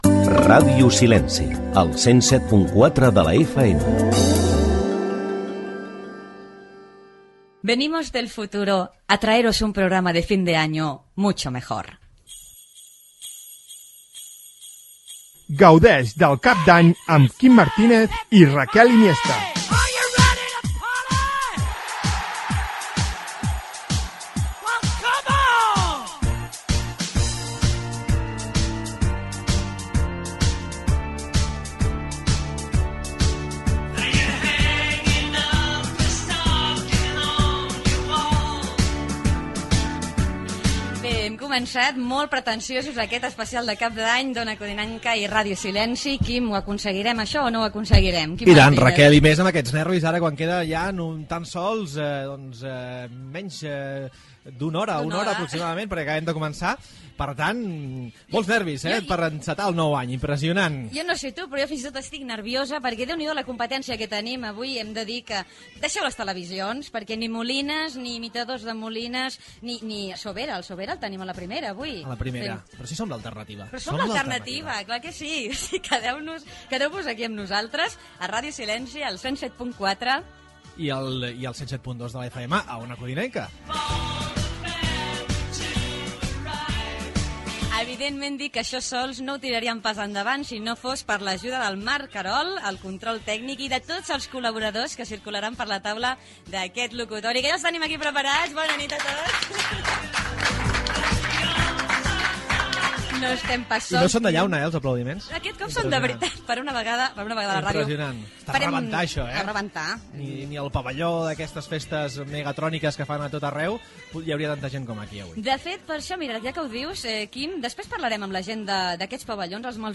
Indicatiu, careta del programa, presentació, equip, sumari
Gènere radiofònic Entreteniment